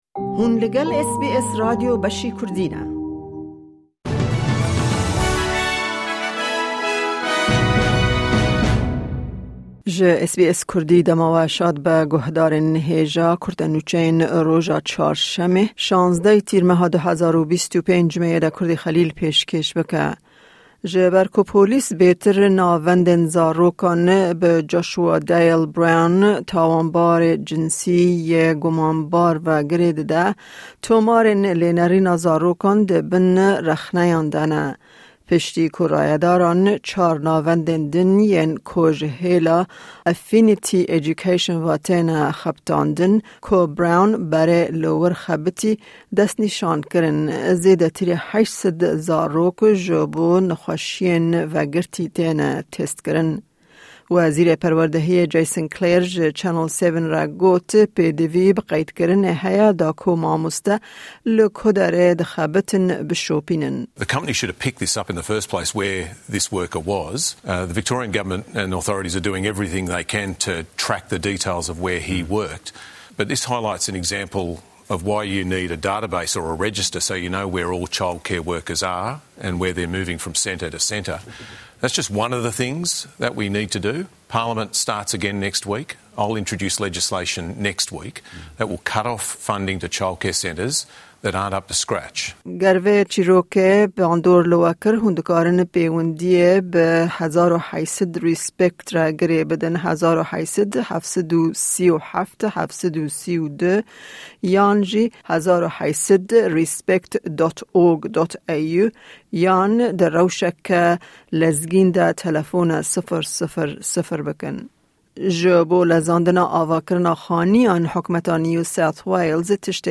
Kurte Nûçeyên roja Çarşemê, 16î Tîrmeha 2025